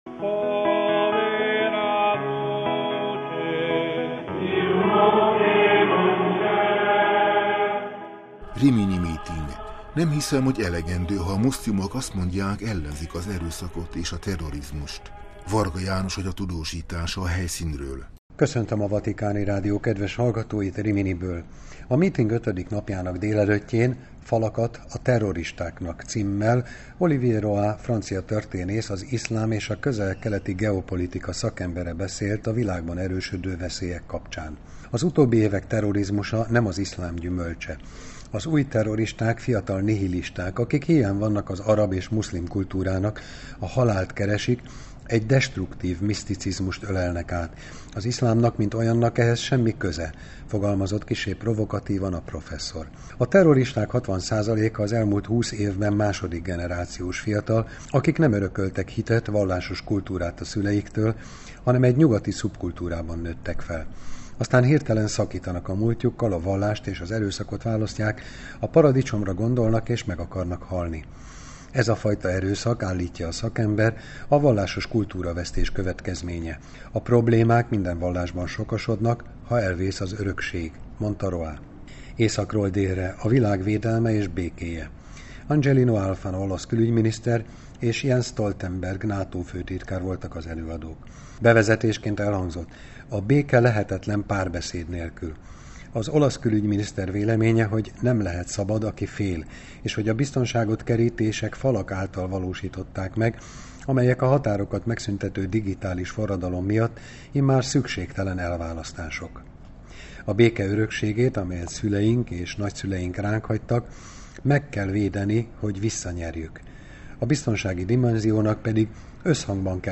Köszöntöm a Vatikáni Rádió kedves hallgatóit Riminiből!